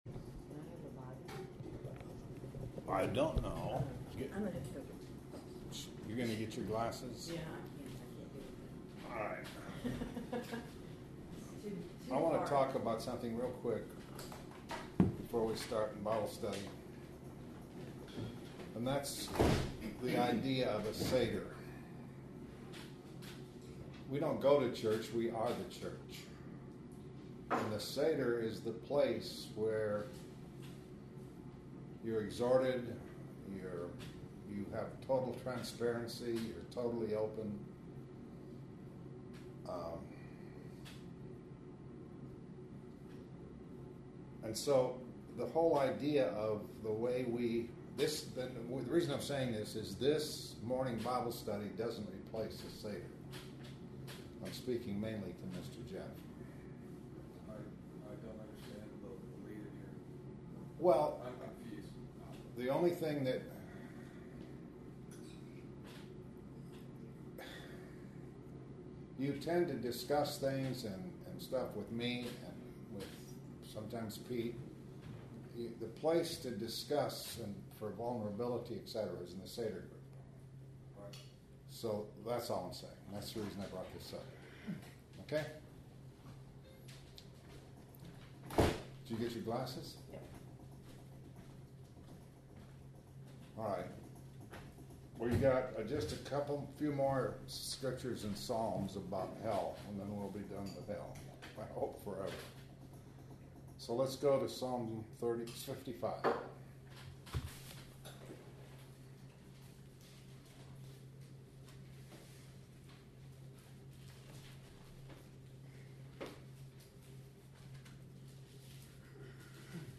Luke 12:5 More on Hell Psalm 55 Psalm 86 This entry was posted in Morning Bible Studies .